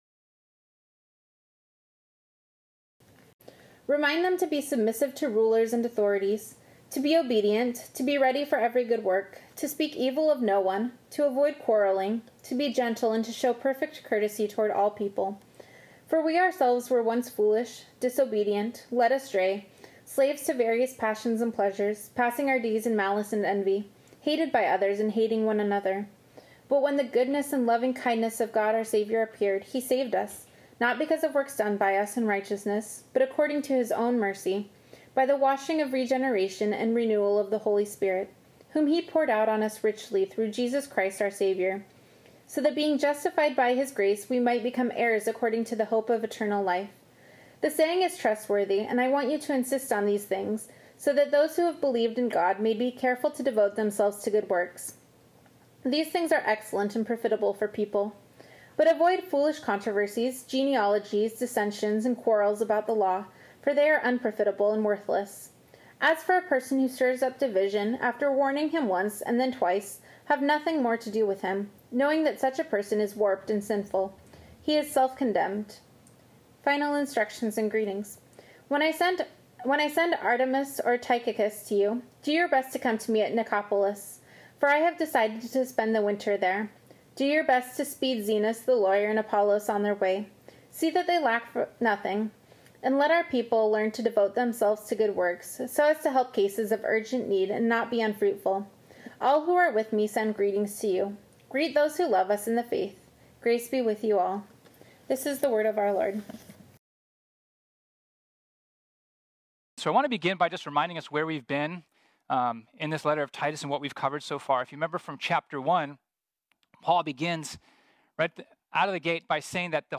This sermon was originally preached on Sunday, March 29, 2020.